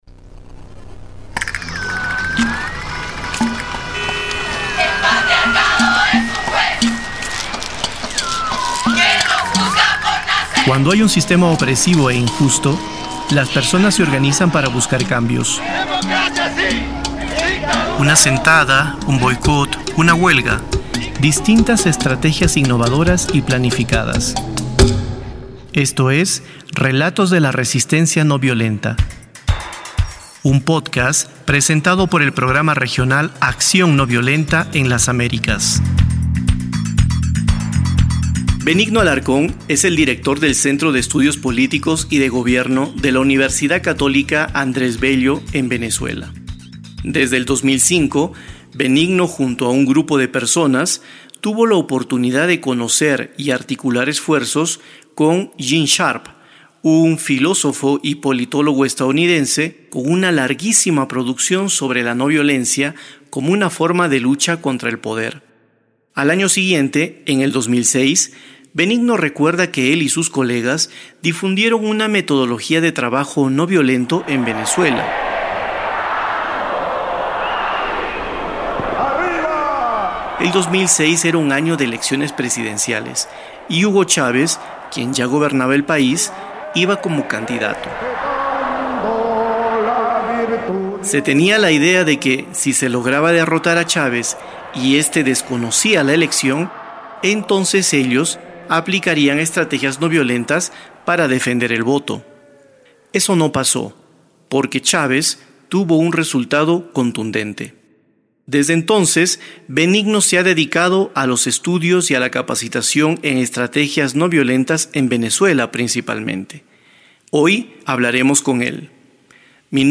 Tamaño: 18.80Mb Formato: Basic Audio Descripción: Entrevista - Acción ...